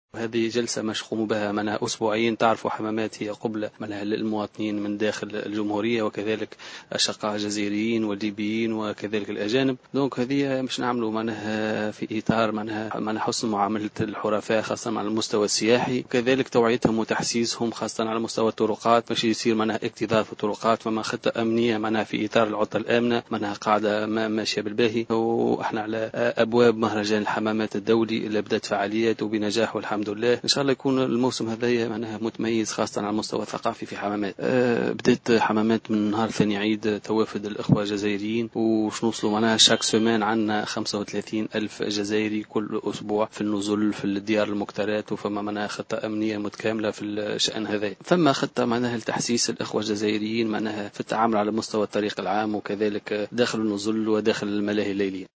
تحدث معتمد الحمامات، حسام بن صغير في تصريح لمراسلة "الجوهرة أف أم" عن آخر الاستعدادات داخل النزل والملاهي الليلية لاستقبال السياح خلال الموسم السياحي الحالي.